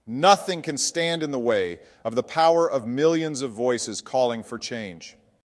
Please note: The voices you hear in this description and the videos are AI generated.
Famous Personalities